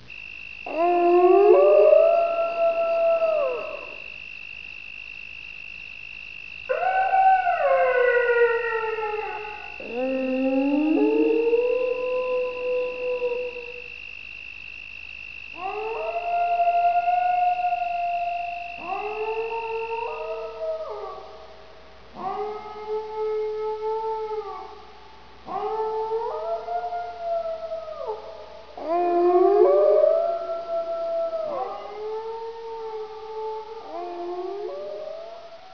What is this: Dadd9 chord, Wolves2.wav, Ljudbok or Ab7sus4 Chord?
Wolves2.wav